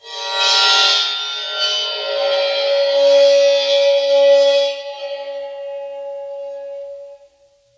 susCymb1-bow-4.wav